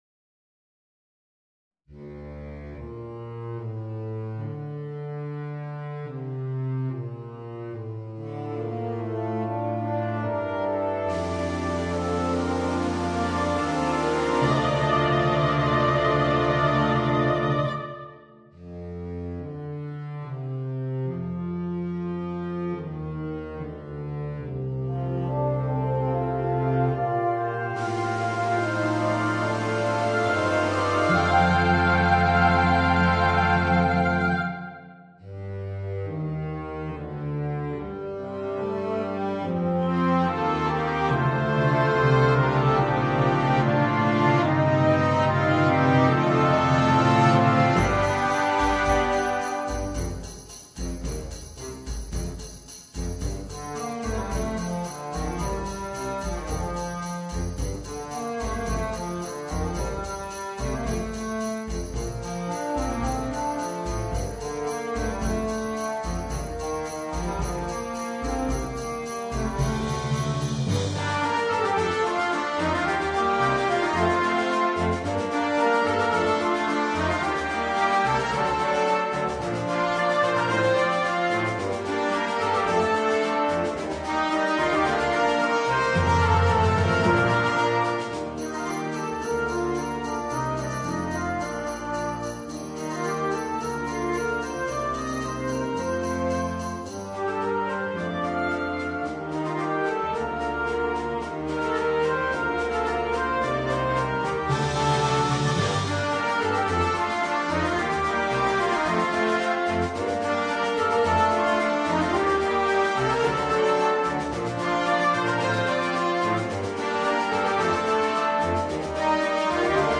Brano da concerto